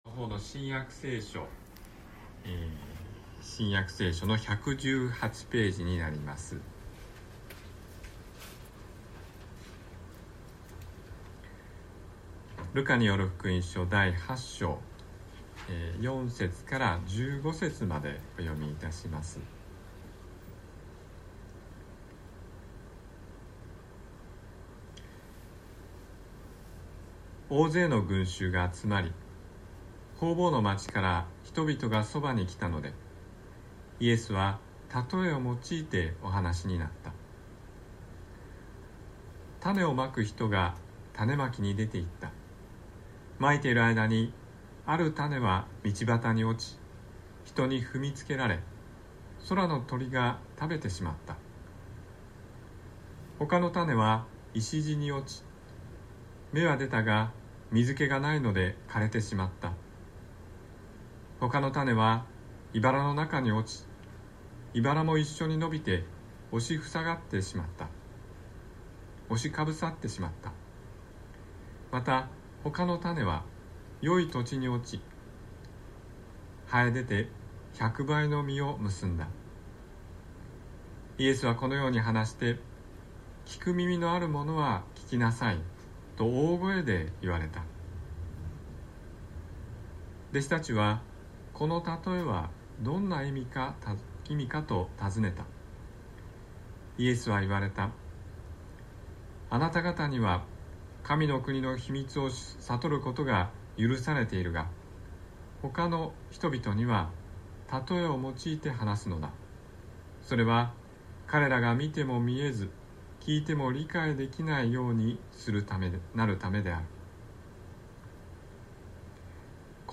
宝塚の教会。説教アーカイブ。